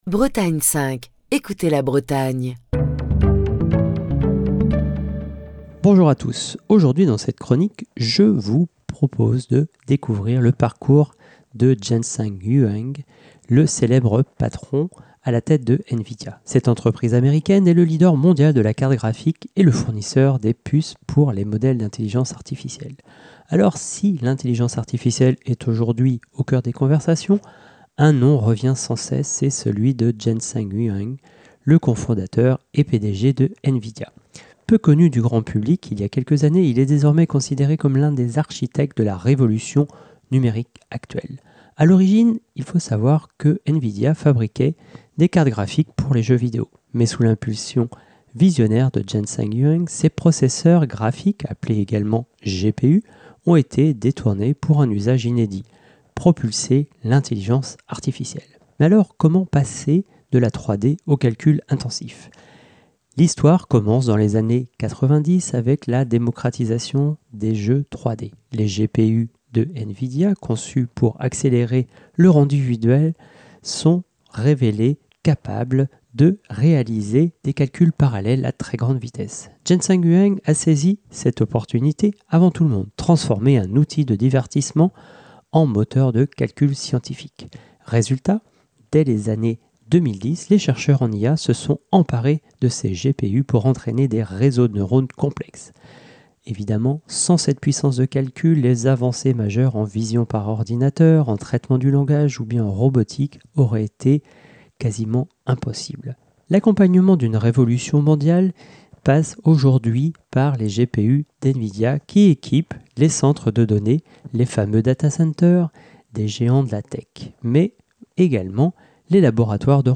Chronique du 14 octobre 2025.